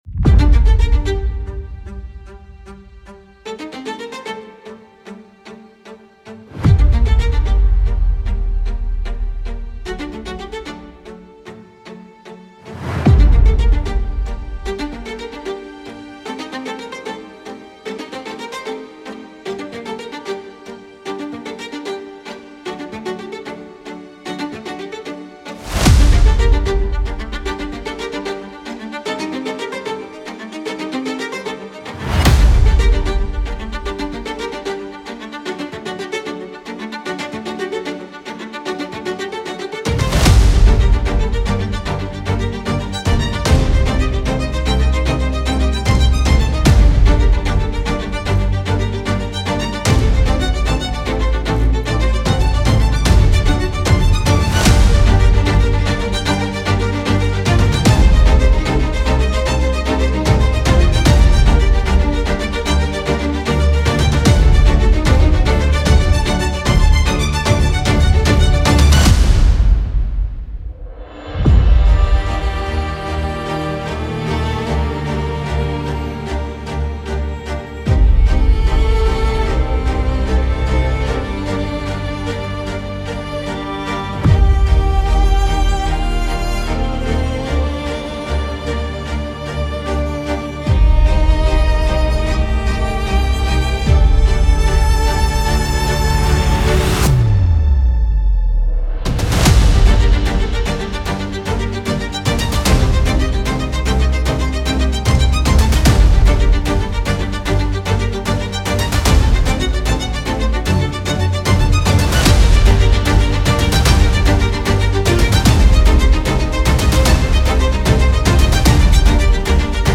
Addictive Chill Melody Leading the 2025 Music Trend